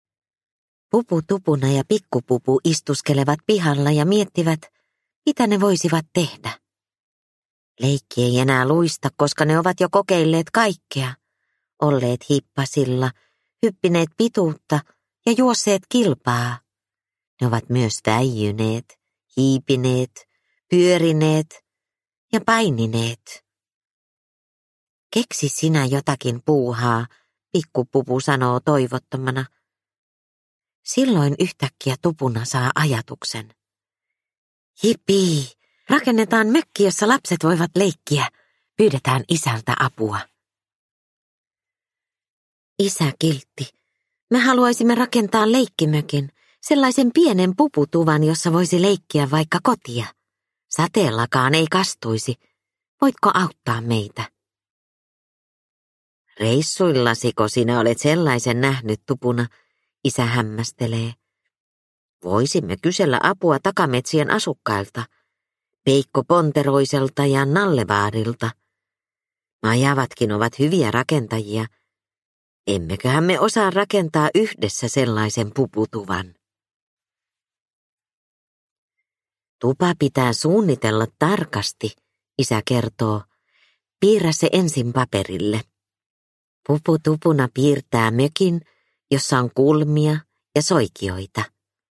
Pupu Tupunan leikkimökki – Ljudbok – Laddas ner